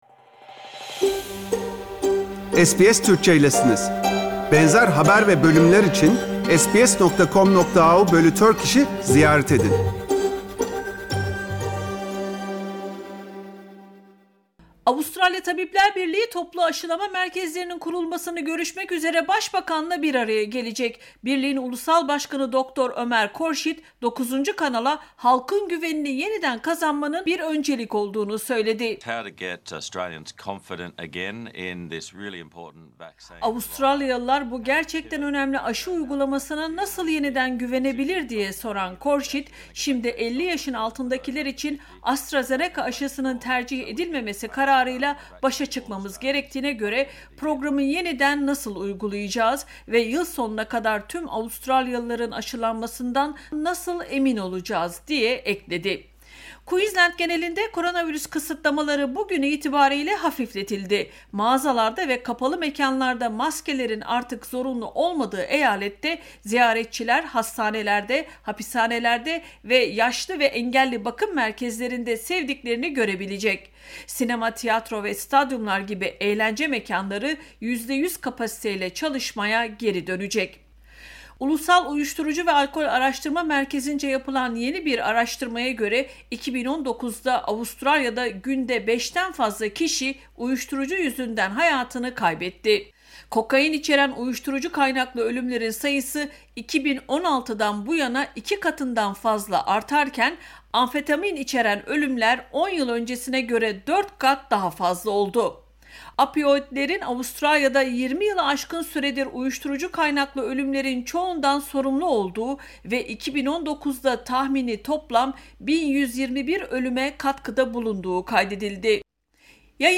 SBS Türkçe Haberler 15 Nisan